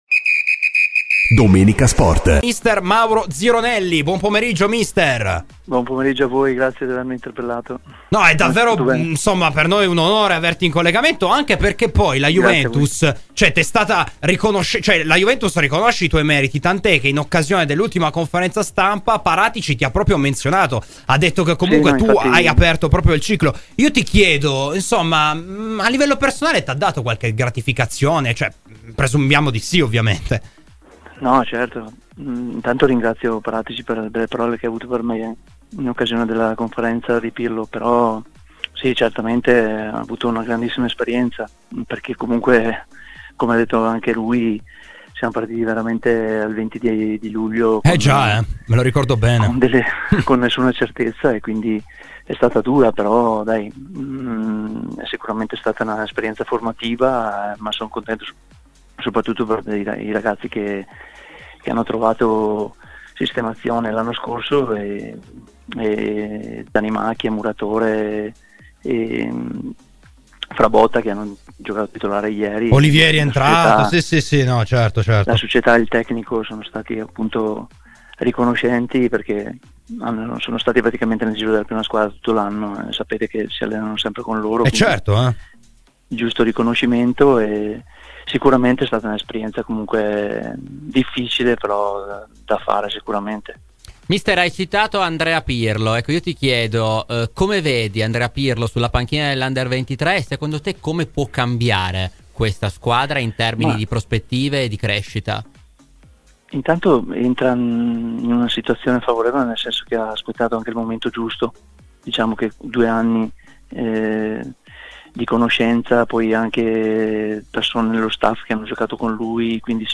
ai microfoni di Domenica Sport
© registrazione di Radio Bianconera